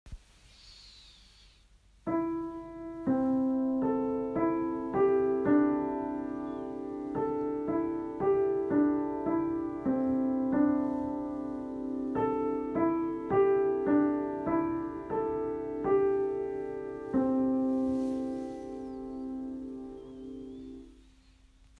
Audio 1: Pentatonik C-Dur
Es entsteht eine harmonische C-Dur-Tonabfolge, welche den Einen oder Anderen zum kurzen Verweilen einlädt. Je nach Windstärke sind die Klänge leiser oder lauter und die Melodie langsamer oder schneller. Durchschnittlich entsteht ca. alle 2 Sekunden einen Ton.